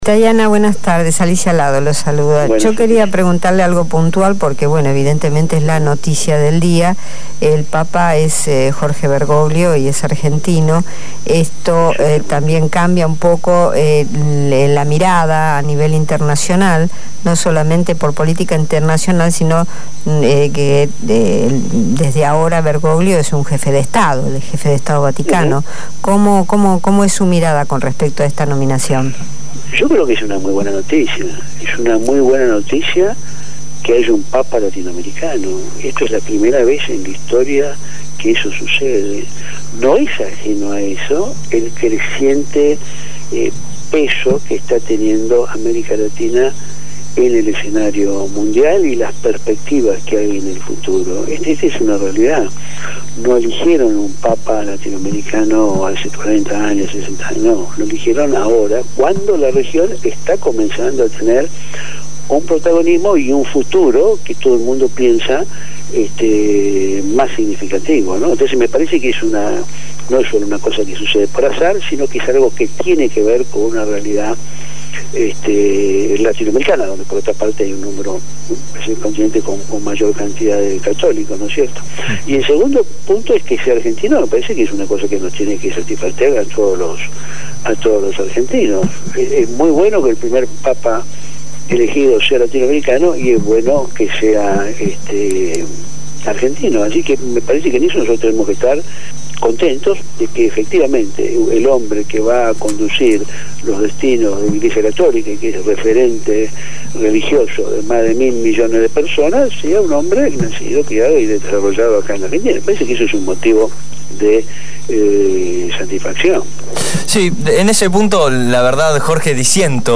Jorge Taiana, ex canciller de la Argentina entre diciembre de 2005 y junio de 2010, Secretario General del Movimiento Evita de la Ciudad de Buenos Aires, habló con el programa “Abramos la Boca”.